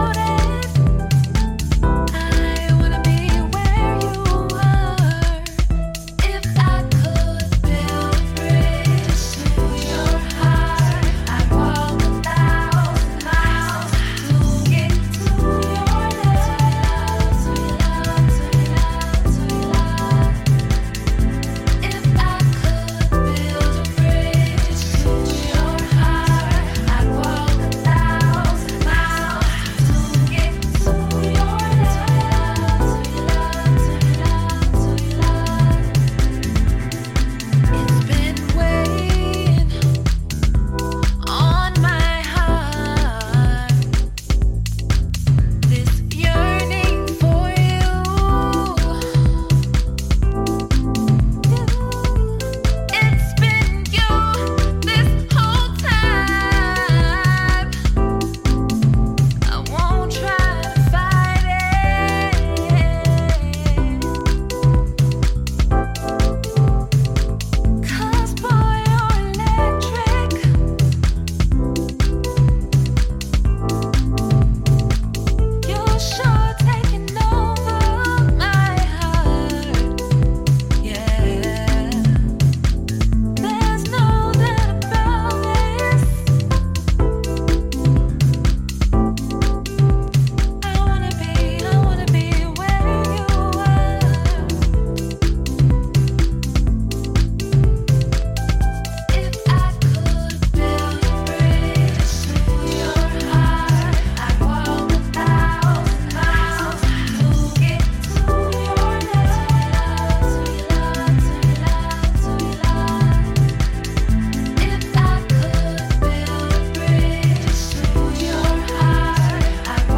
ジャンル(スタイル) SOULFUL HOUSE / DEEP HOUSE